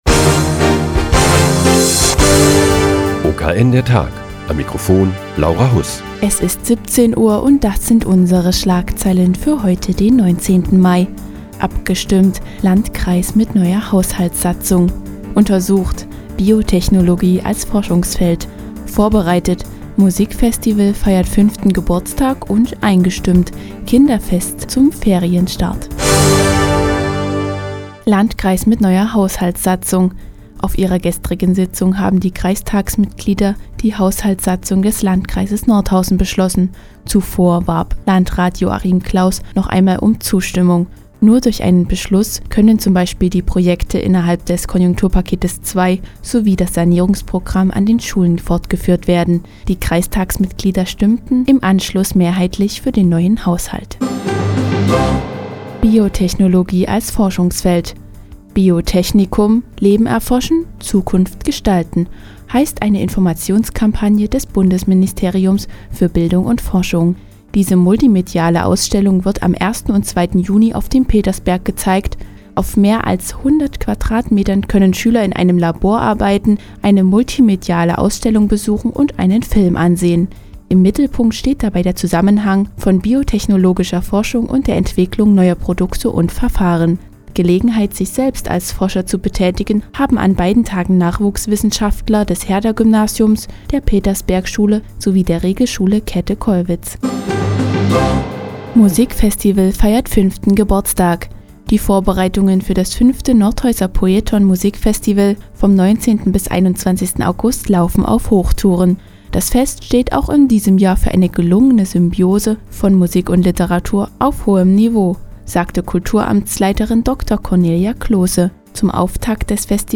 Die tägliche Nachrichtensendung des OKN ist nun auch hier zu hören.